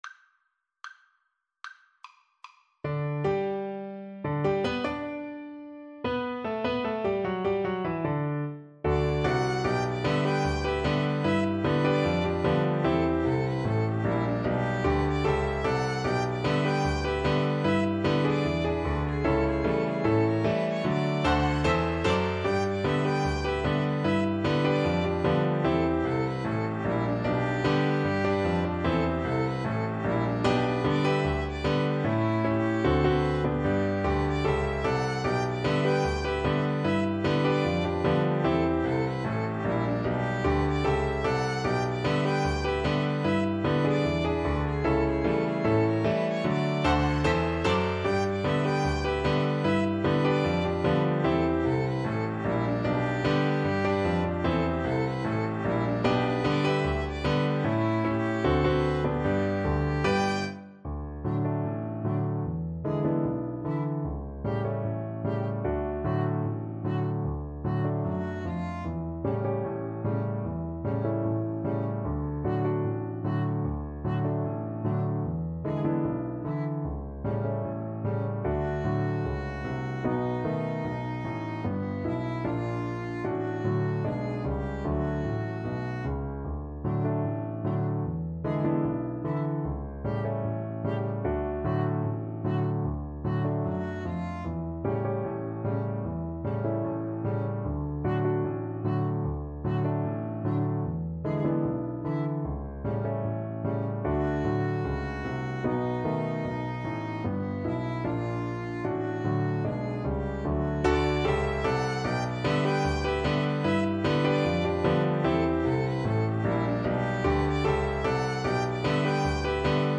= 75 Not fast INTRO.
2/4 (View more 2/4 Music)